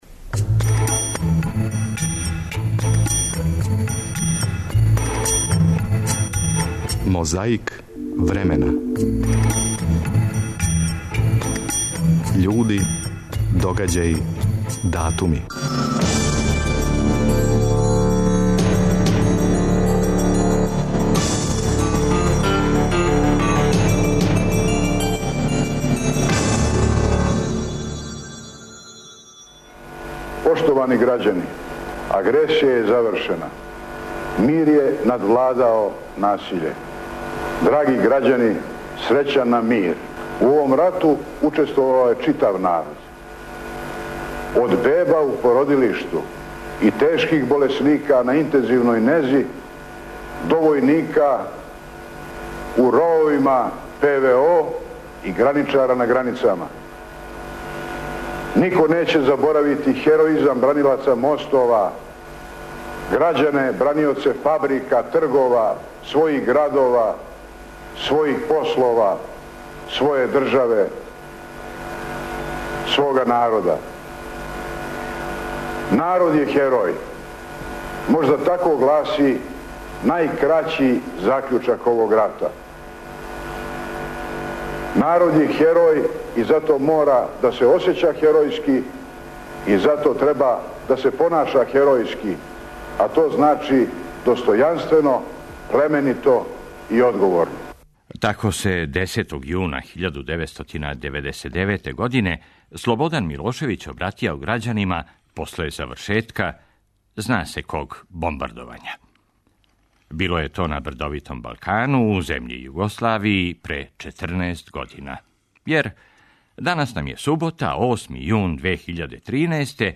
10. јуна 1946. године почело је суђење Дражи Михајловићу. Слушамо снимак са седмог дана суђења.